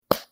thunder fart
thunder-fart.mp3